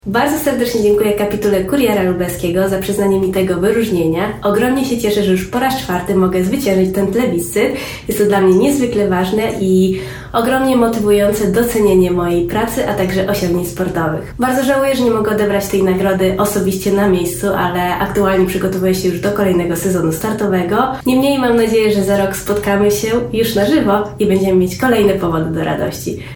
-skomentowała na nagraniu wideo odtworzonym podczas uroczystej gali Aleksandra Mirosław.